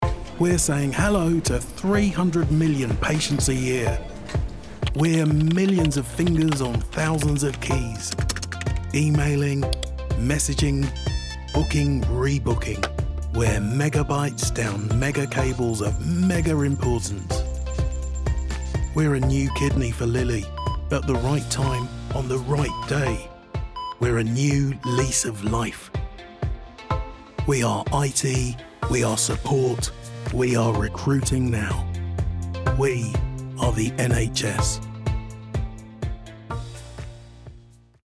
40/50's London/RP Characterful/Relaxed/Versatile
Commercial Showreel Army BT TFL